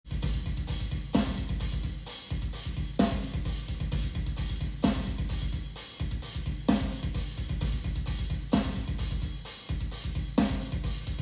ドラムを入れる
かなりドスドスしたヘビメタルなドラムパターンをドラム音源から
良い感じのバスドラがうるさいメタルなパターンがありました。